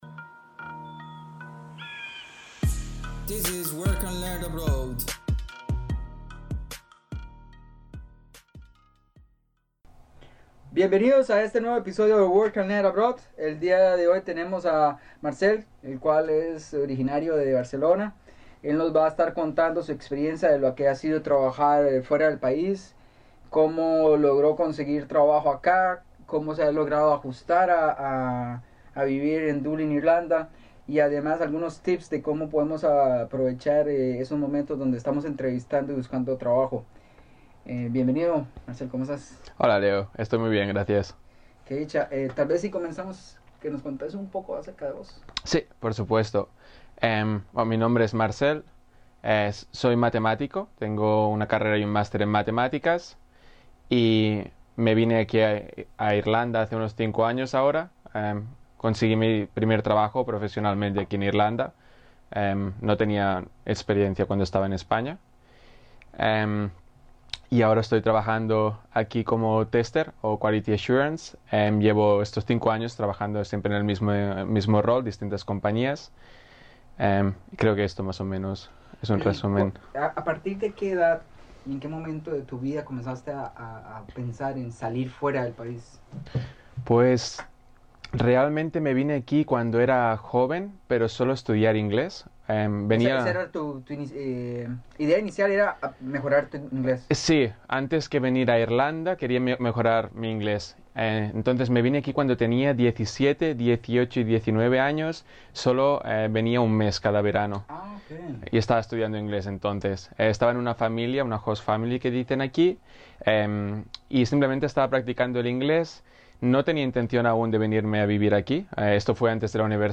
第42 Trabajar en el extranjero – Entrevista